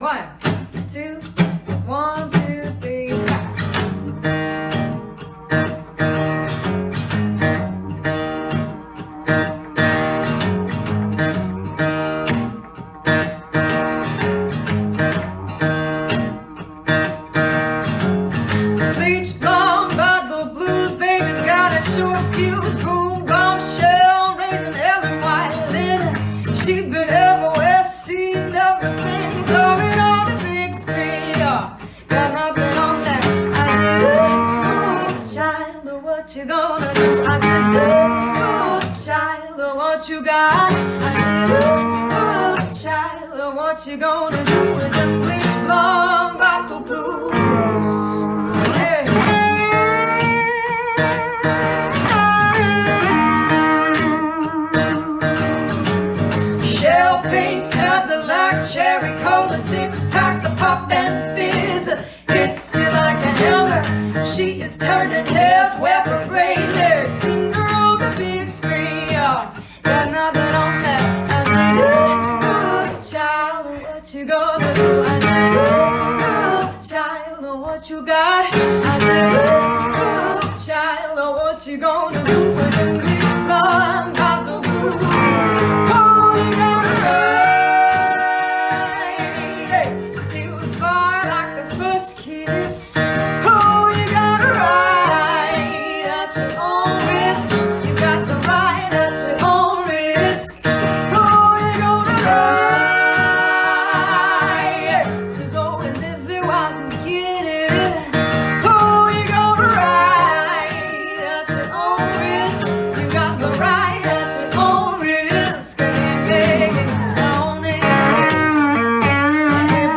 Blues
en el que las Blueswomen y Bluesmen harán su parte para acabar con el virus tocando desde casa